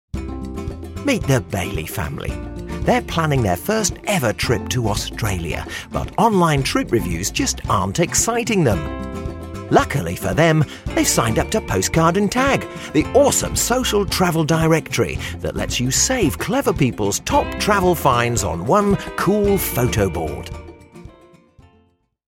Lively sell